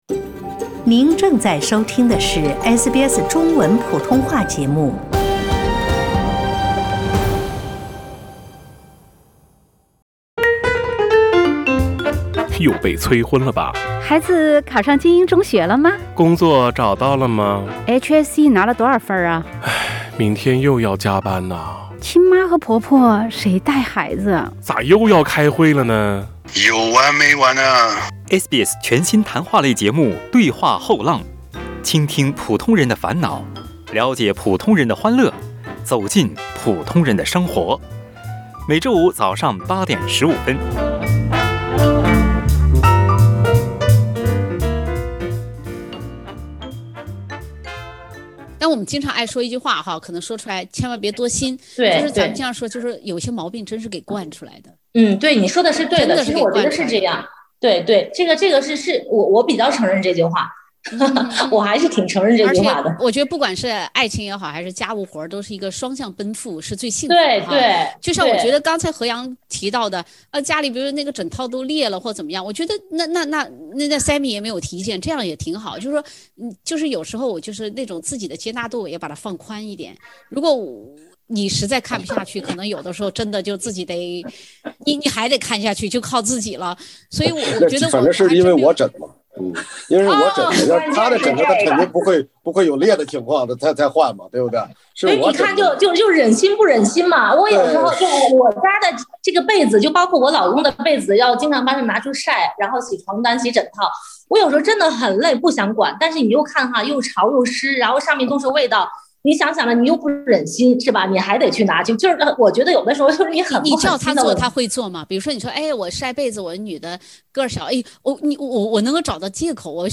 从表面看，家务活大部分看起来貌似都没有太多意义，也不产生什么经济效益。（点击封面图片，收听有趣对话）